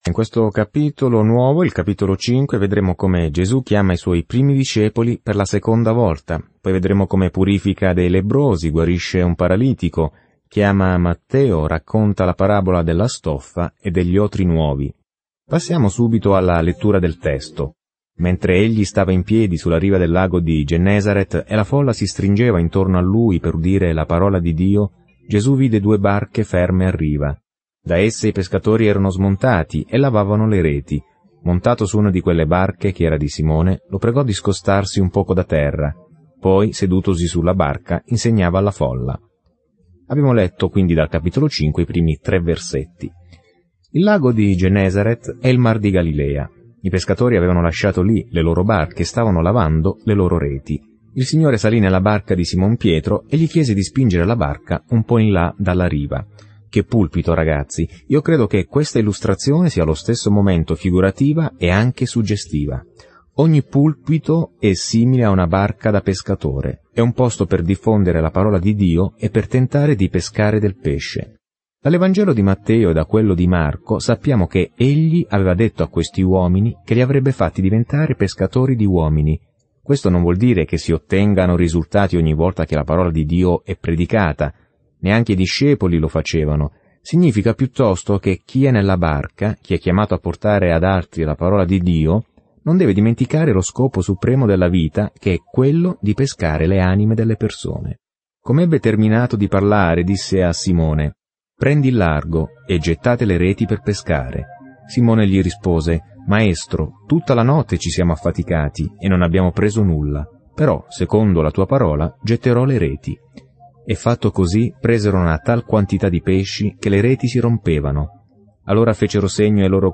Scrittura Vangelo secondo Luca 5 Giorno 6 Inizia questo Piano Giorno 8 Riguardo questo Piano Testimoni oculari informano la buona notizia che Luca racconta della storia di Gesù dalla nascita alla morte fino alla risurrezione; Luca racconta anche i Suoi insegnamenti che hanno cambiato il mondo. Viaggia ogni giorno attraverso Luca mentre ascolti lo studio audio e leggi versetti selezionati della parola di Dio.